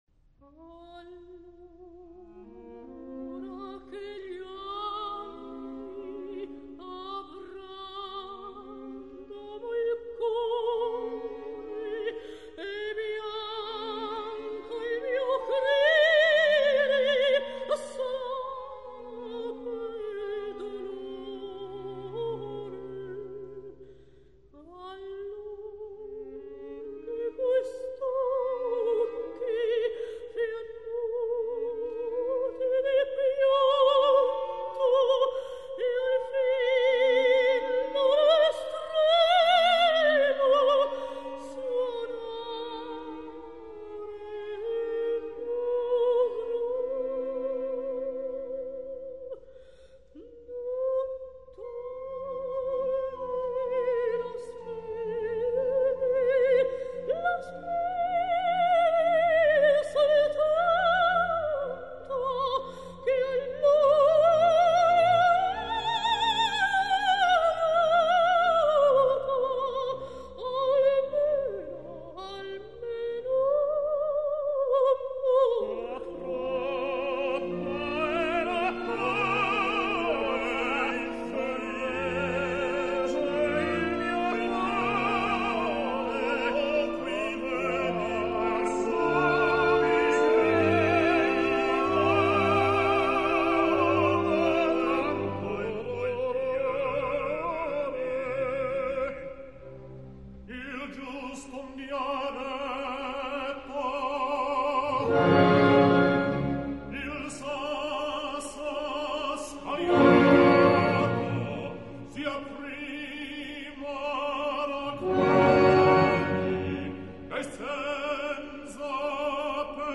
Mina [Sopran]
Egberto [Bariton]
Briano [Bass]